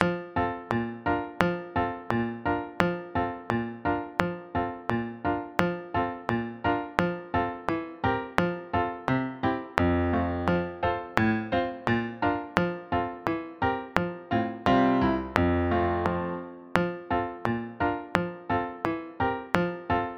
Tempo 86 z metronomem